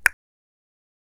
claquement-2.wav